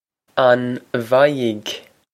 Pronunciation for how to say
On vie-g?
This is an approximate phonetic pronunciation of the phrase.